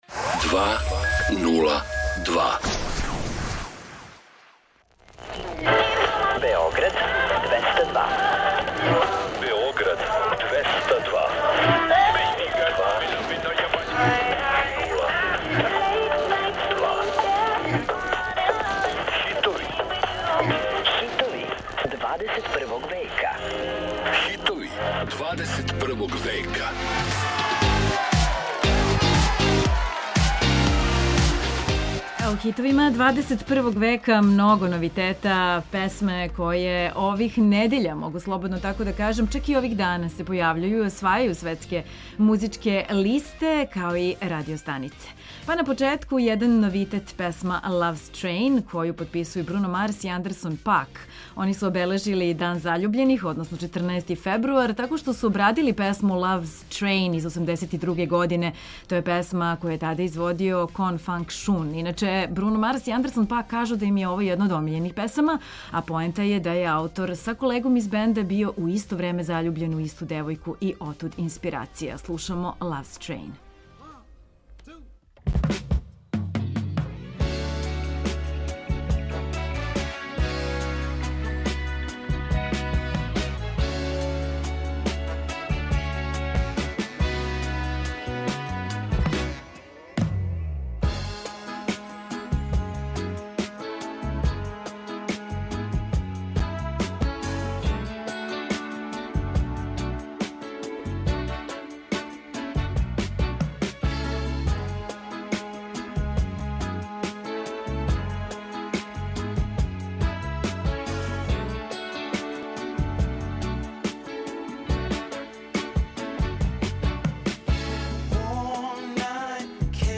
Slušamo hitove novog milenijuma, koji osvajaju top liste i radijske stanice širom sveta.